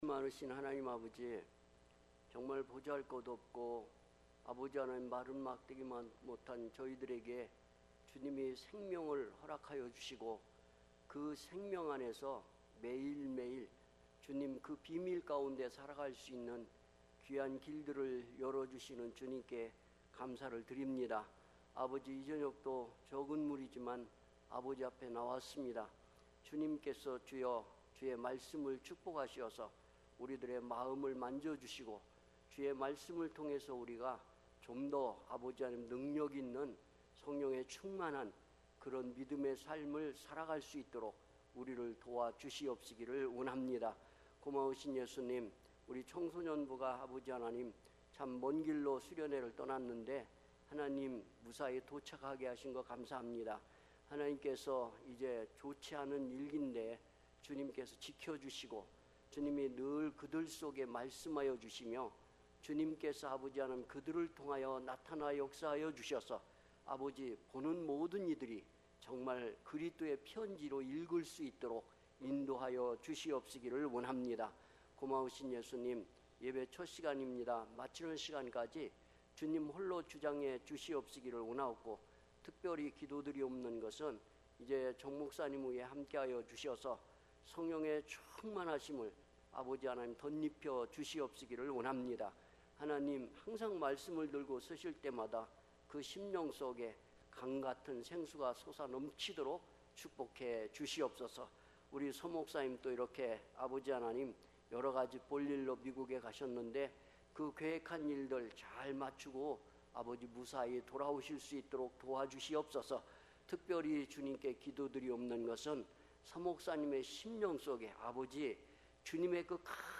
특별집회 - 요한복음 4장 1-14절